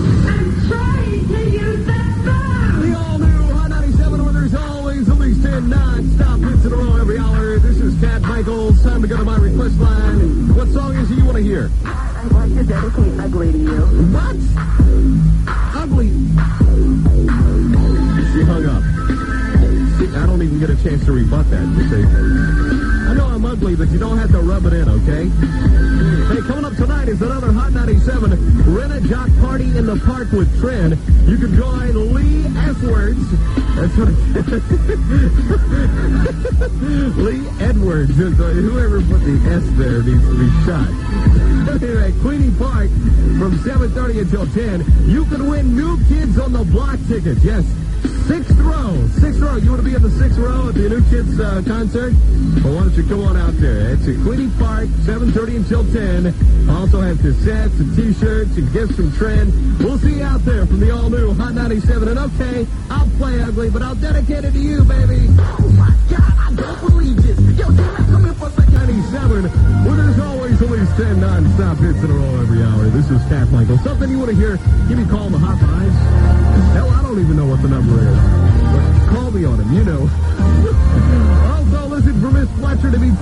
Original Format aircheck